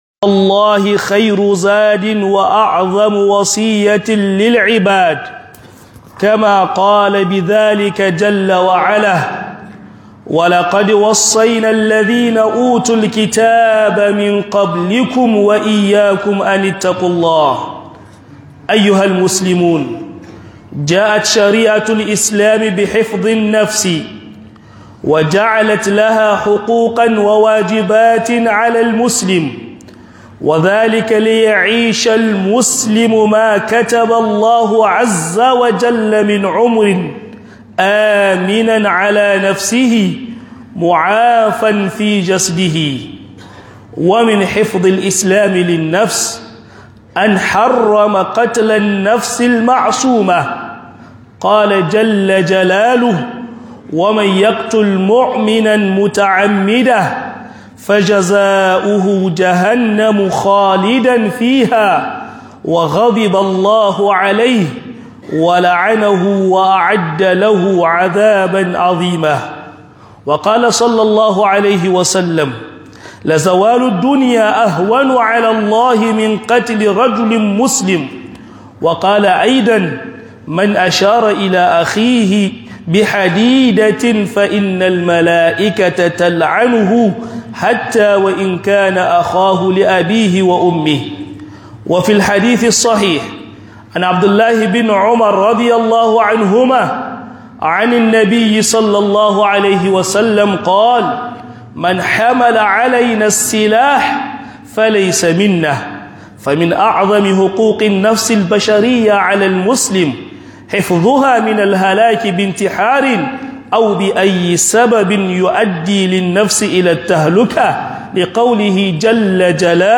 Khutubar Juma'a Hukunce Hukuncen Azumi